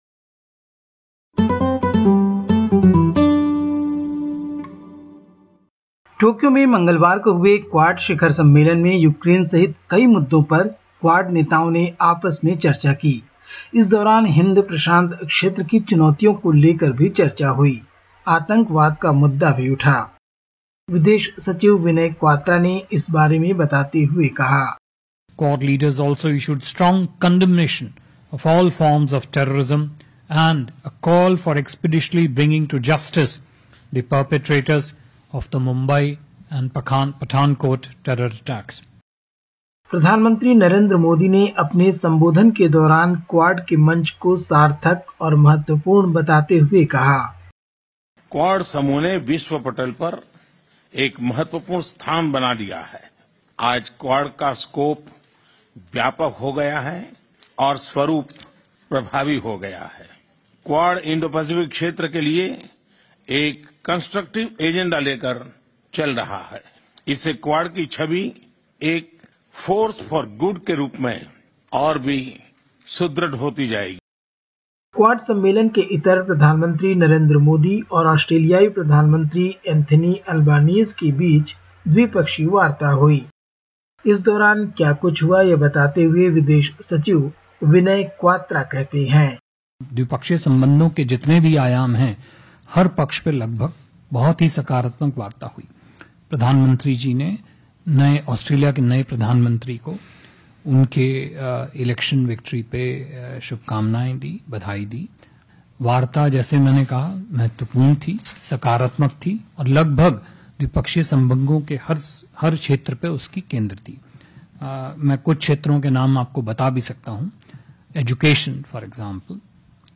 Listen to the latest SBS Hindi report from India. 25/05/2022